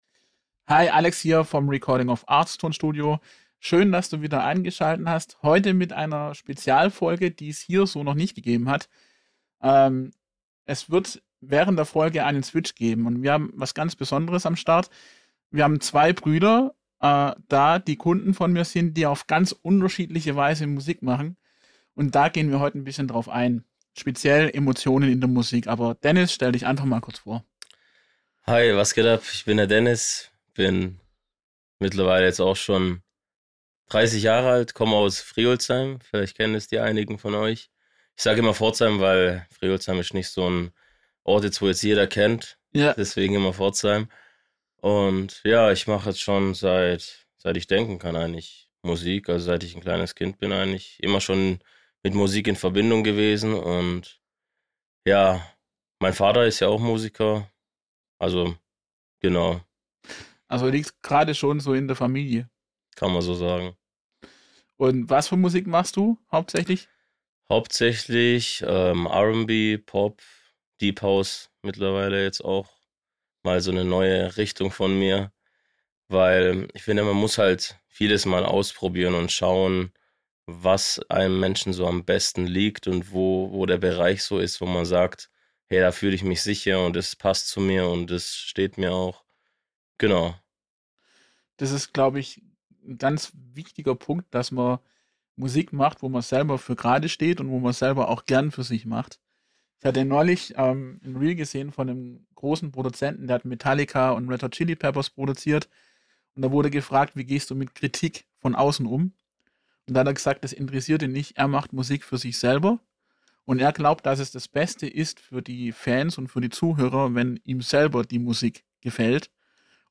Ein ehrliches Gespräch über Musik, Wachstum, Teamgeist – und die einfache Wahrheit: Große Songs entstehen nie im Alleingang.